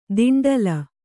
♪ diṇḍala